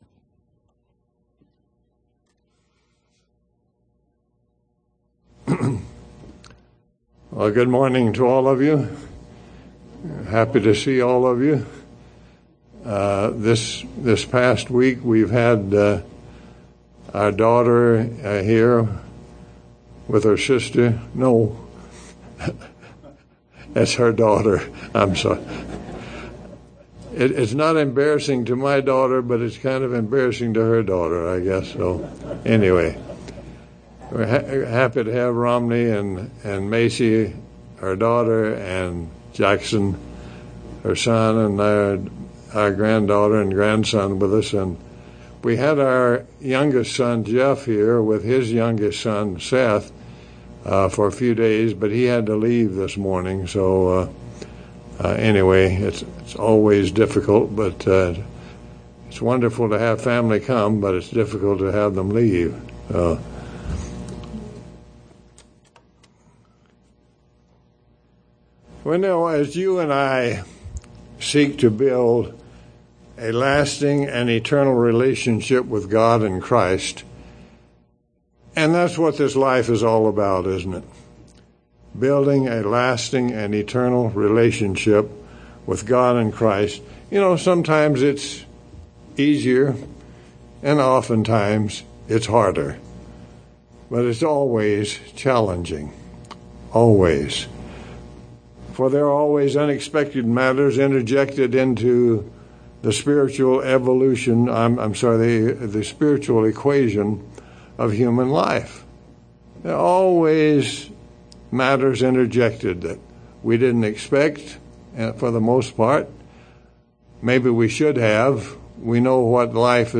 There are many ways in which both God and Christ have the oversight of our spiritual lives. This sermon reviews a few ways in which God enriches our lives, guides us, and prepares us to become a member of His family at the return of Christ.